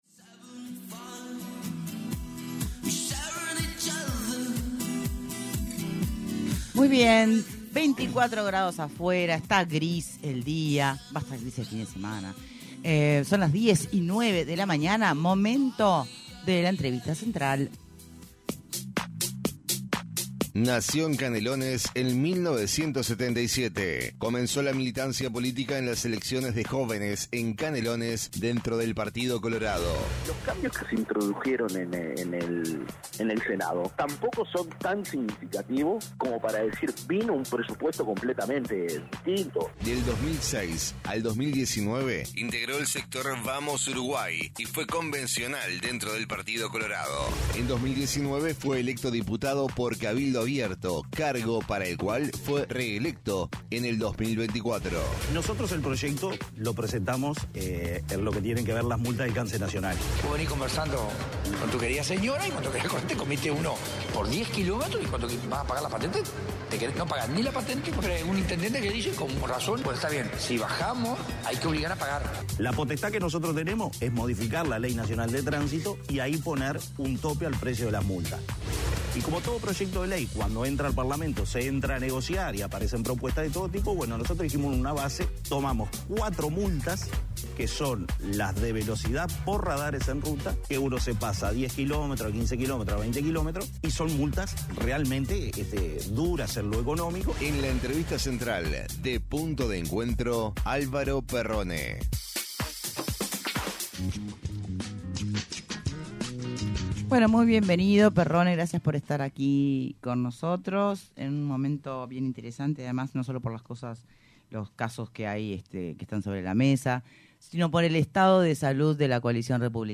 ENTREVISTA: ALVARO PERRONE
En entrevista con Punto de Encuentro, el diputado de Cabildo Abierto, Álvaro Perrone dijo que interpreta que el Partido Nacional defiende el contrato con Cardama para no reconocer la equivocación que fue firmarlo y señaló que de parte de la empresa hay un “intento de estafa”.